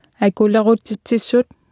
Below you can try out the text-to-speech system Martha.
Speech synthesis Martha to computer or mobile phone
Speech Synthesis Martha